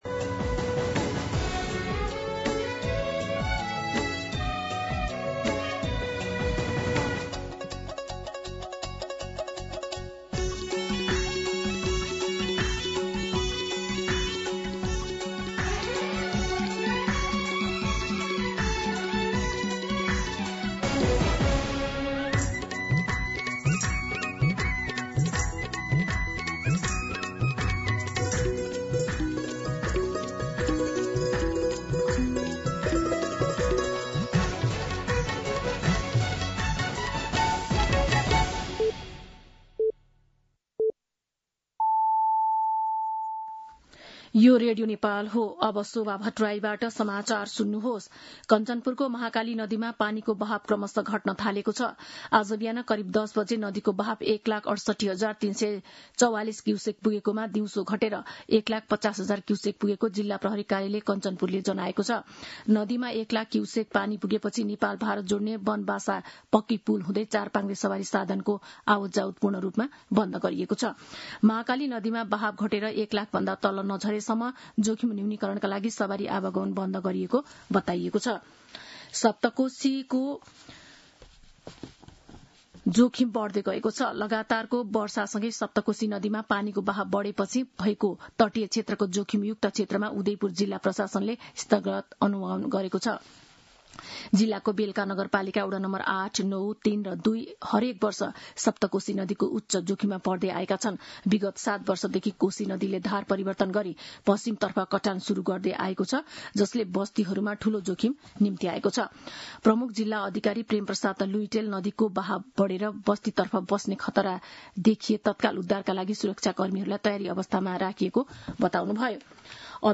दिउँसो ४ बजेको नेपाली समाचार : १९ साउन , २०८२